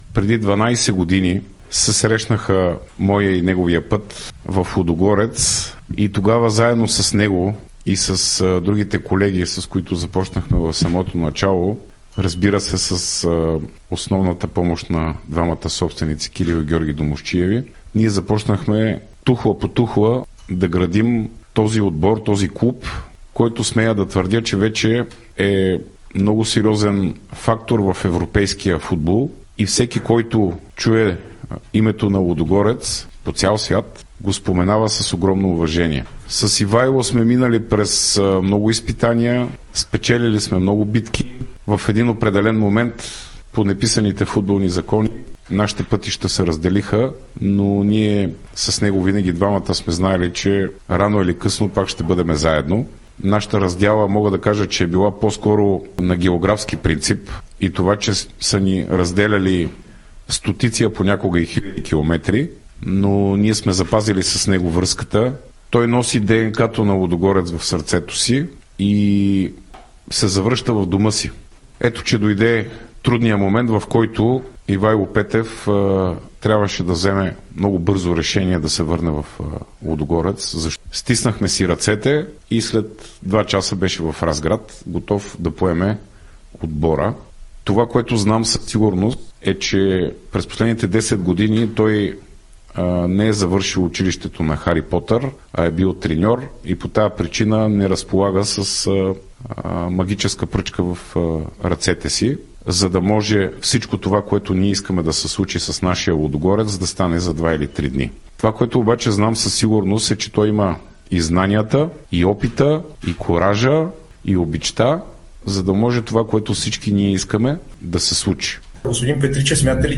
съвместна пресконференция